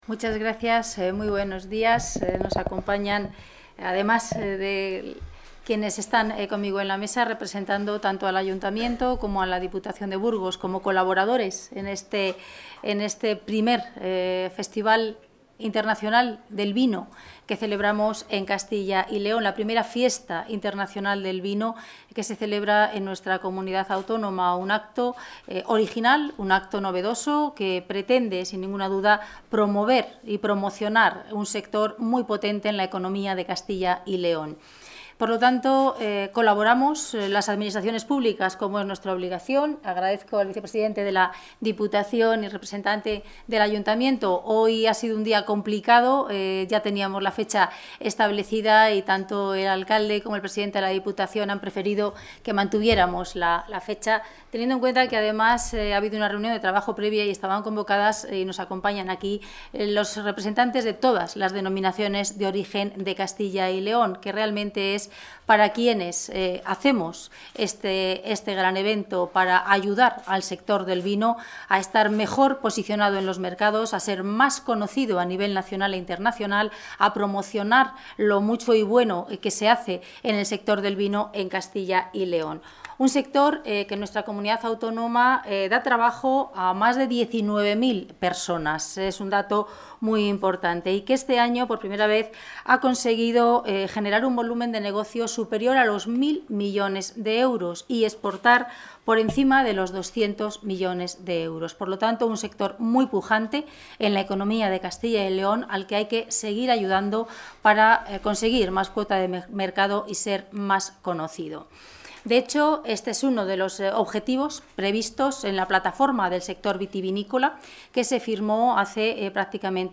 Intervención de la consejera de Agricultura y Ganadería.